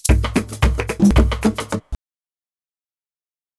133SHAK02.wav